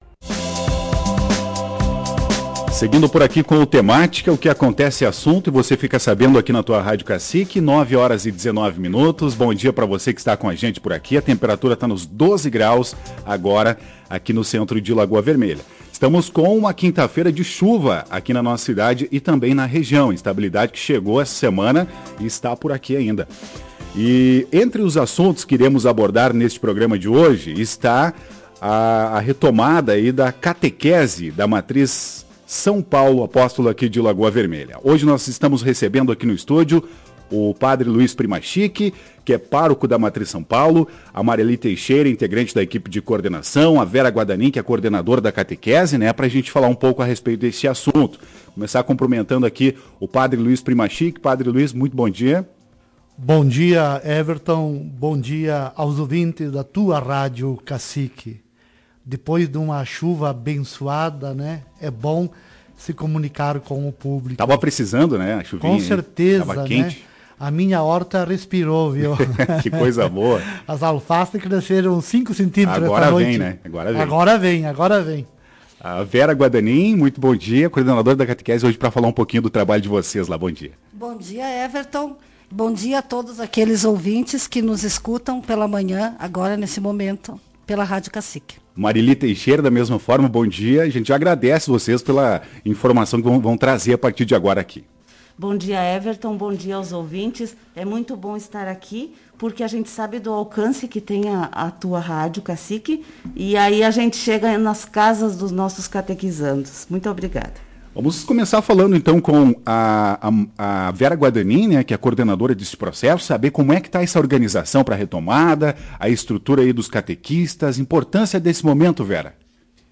Em entrevista à Tua Rádio Cacique